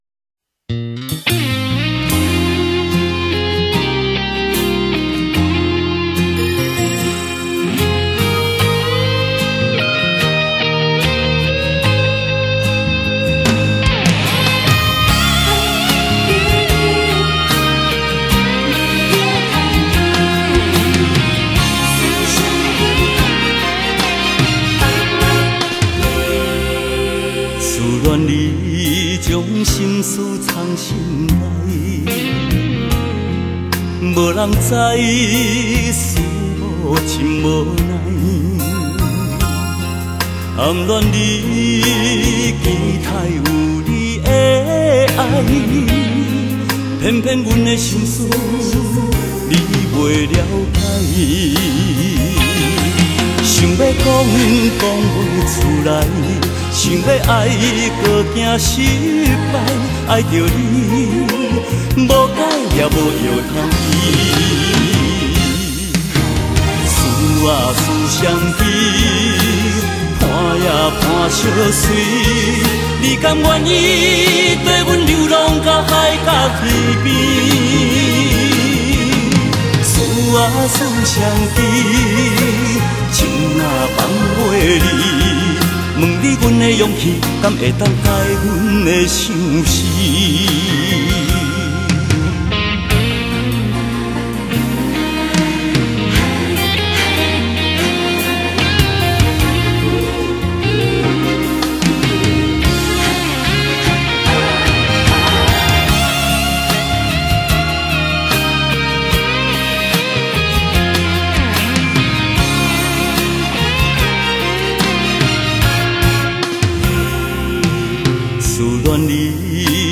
他独树一格的演唱风格，深受日本演歌影响，歌唱特色明显、
稳定性佳的演歌力道、再加上男性的特有哭腔，在台湾歌坛来说是不可多得的歌手。